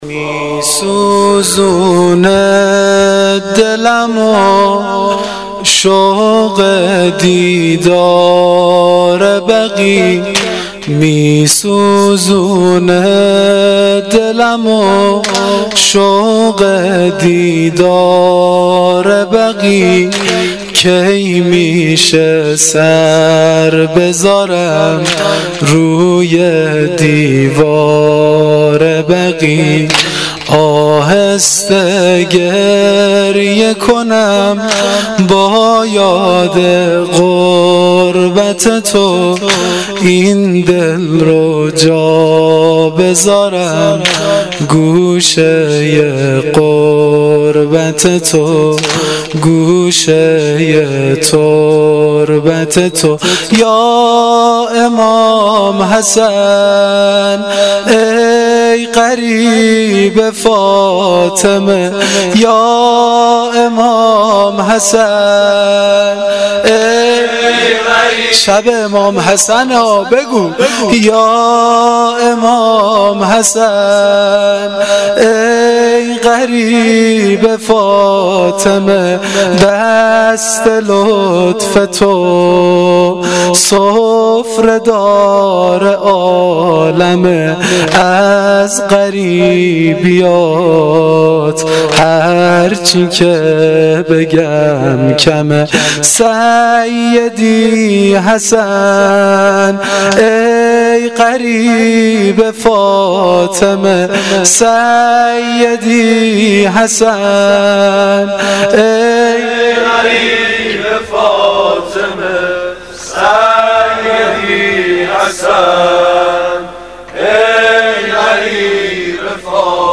واحد سنگین شب ششم محرم الحرام 1396
روضه